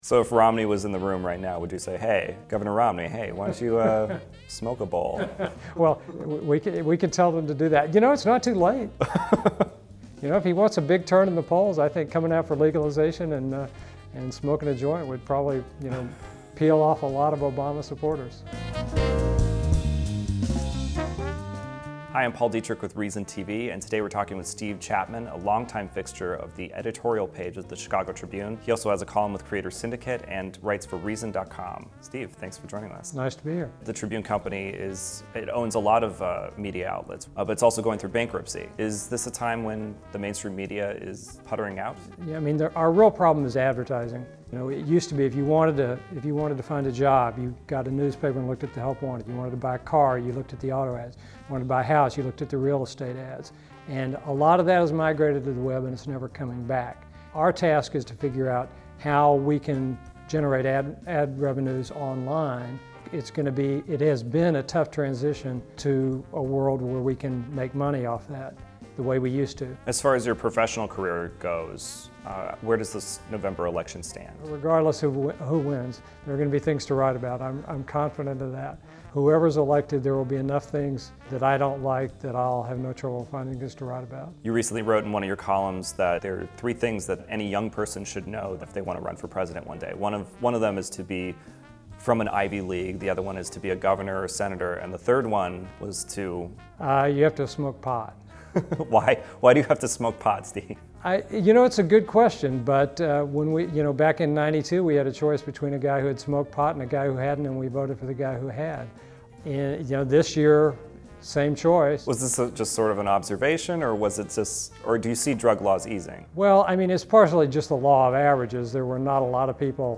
C-mol blues (Jazz at Mladost) / CC BY-NC-SA 3.0